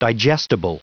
Prononciation du mot digestible en anglais (fichier audio)
Prononciation du mot : digestible